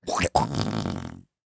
splat-v1.ogg